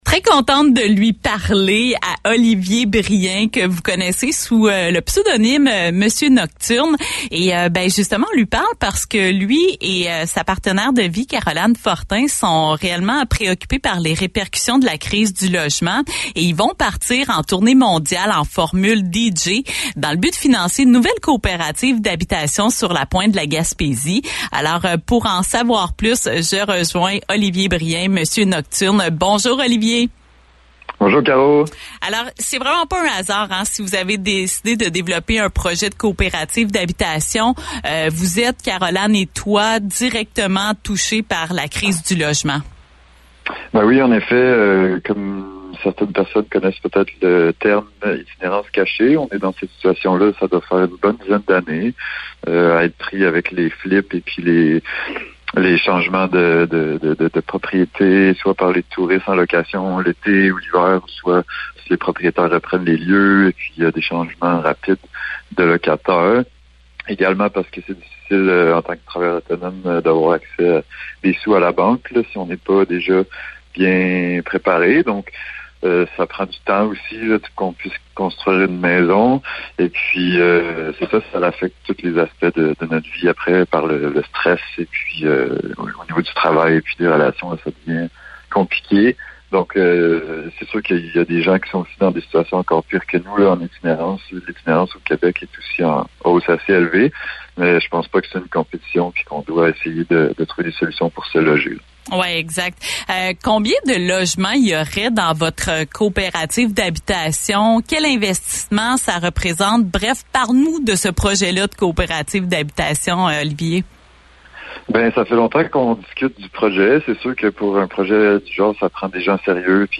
Pour en savoir sur les problématiques d’accessibilité au logement et sur cette tournée, je les rejoins à Grande-Rivière.